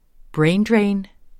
Udtale [ ˈbɹεjnˌdɹεjn ]